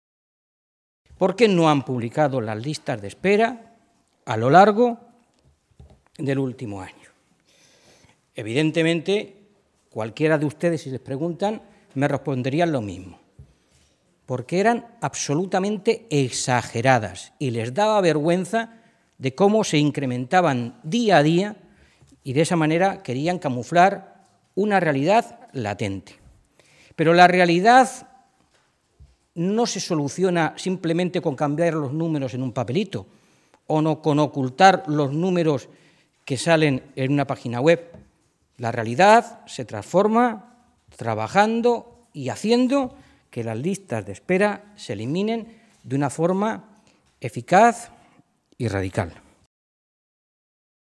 Mora se pronunciaba de esta manera esta mañana en Toledo, en una comparecencia ante los medios de comunicación poco antes de que el consejero de Sanidad, José Ignacio Echániz, ofreciera una rueda de prensa en la que va a presentar la nueva página web del Servicio de Salud de Castilla-La Mancha y va a ofrecer los datos sobre las listas de espera en la región, que el Gobierno de Cospedal dejó de publicar en enero de este año.
Cortes de audio de la rueda de prensa